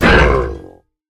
sounds / mob / ravager / hurt4.ogg
hurt4.ogg